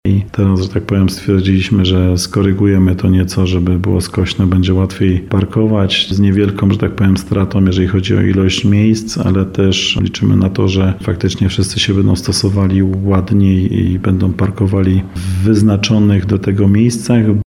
– Sprawa jest bardzo prozaiczna – wyjaśnia wiceprezydent miasta Przemysław Kamiński.